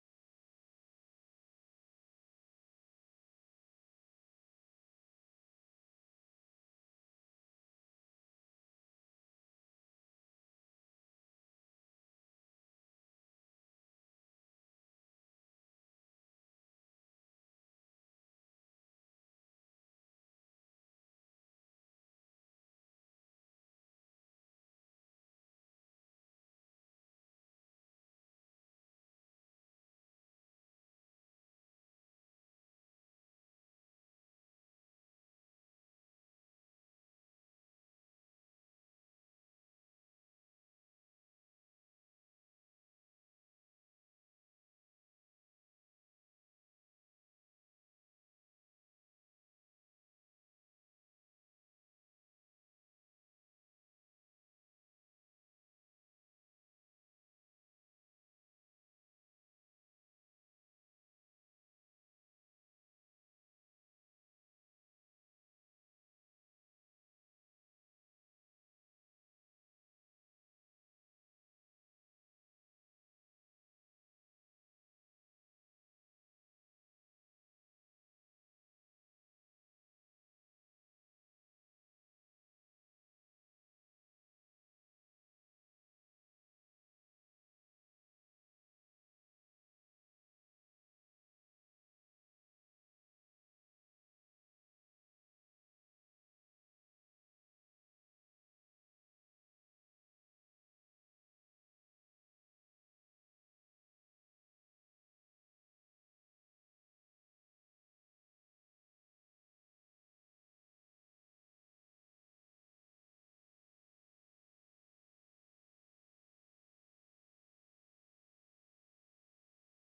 Live remix of NYC radio from Brooklyn on Hudson Valley frequencies.